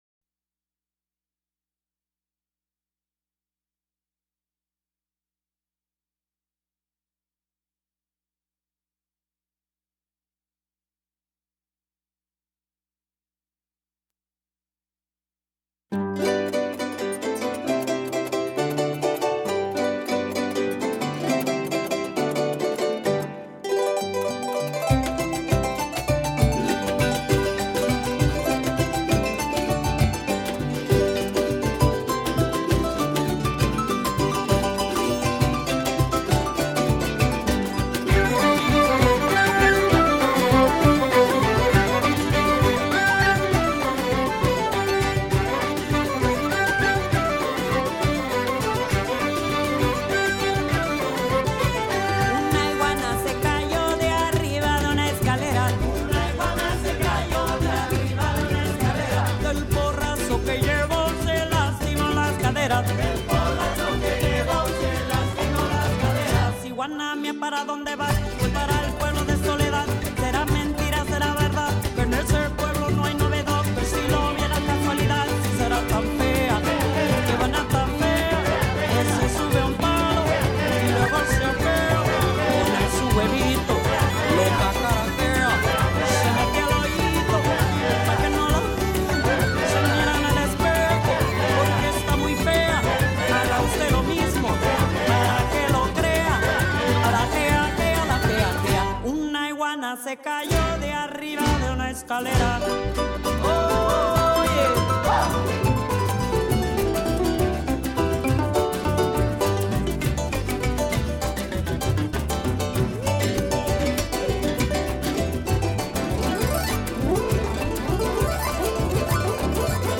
Interview with Paddy Moloney
01 Interview with The Chieftan's Paddy Moloney.mp3